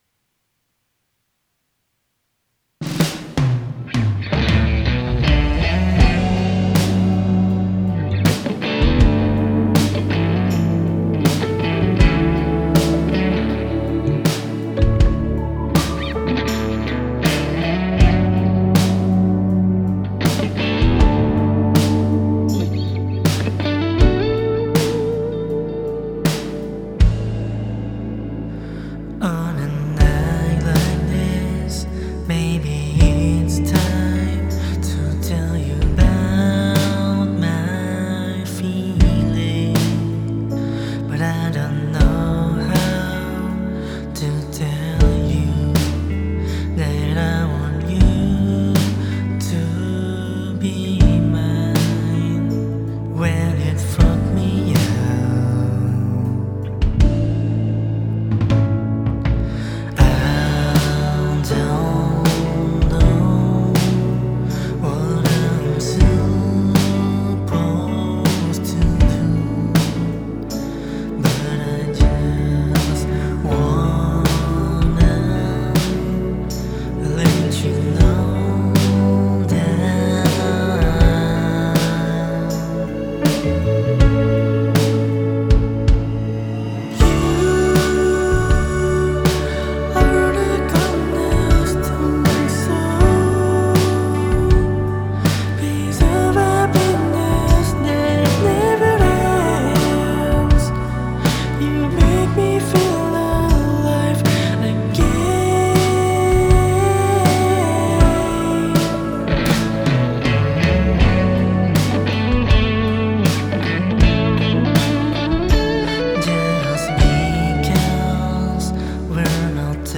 Alternative Pop • Ponorogo